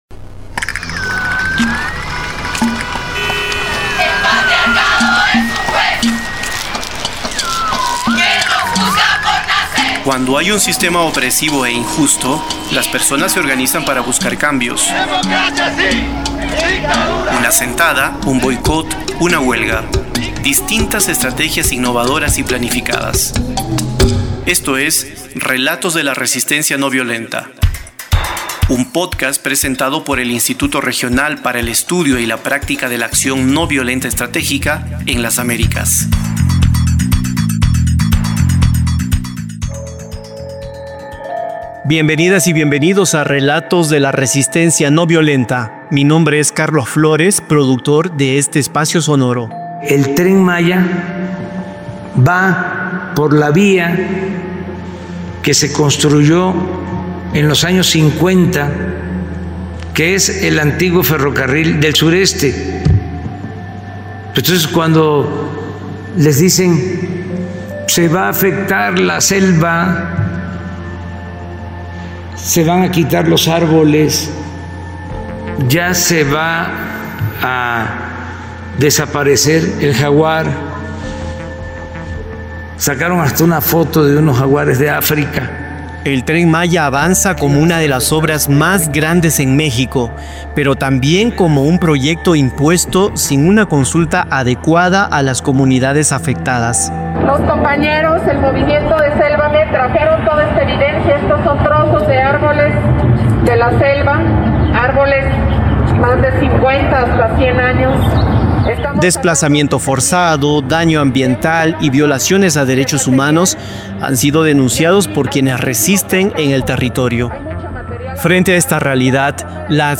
Entrevista - Acción Noviolenta